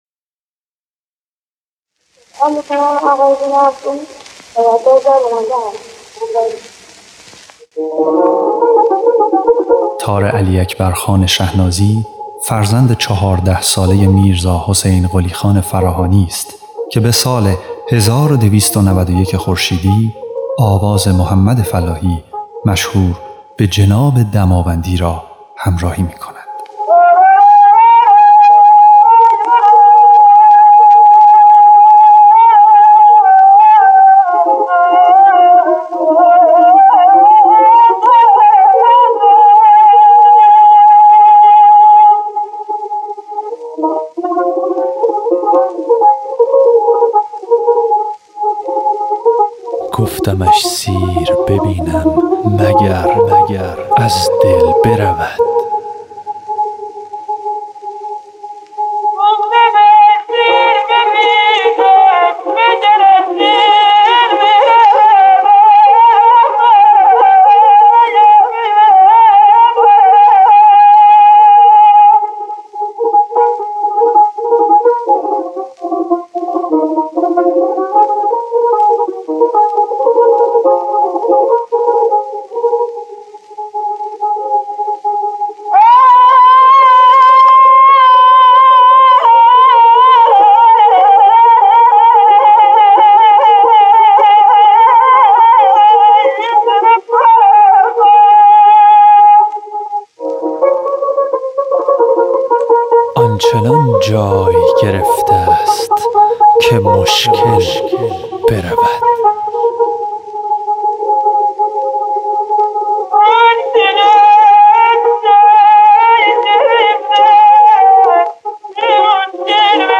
ضبط سری اول آثار
خواننده
نوازنده تار